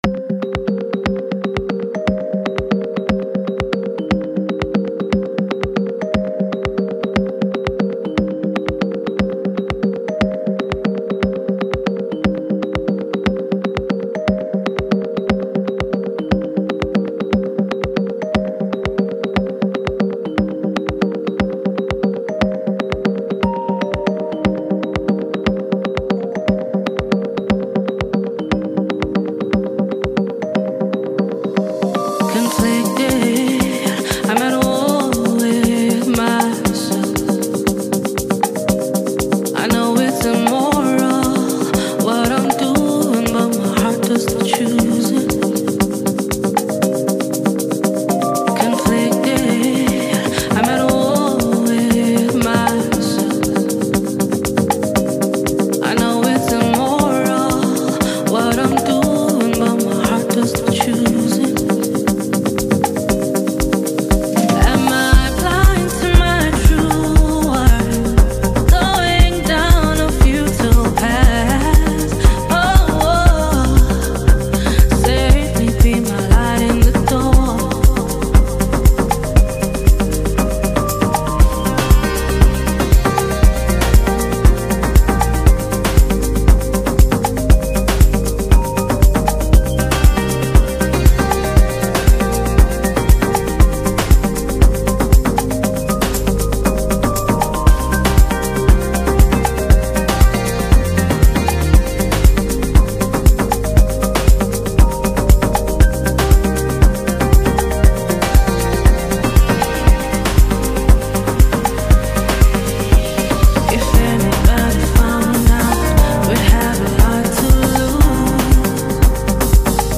a captivating blend of deep house and soulful vocals
electronic music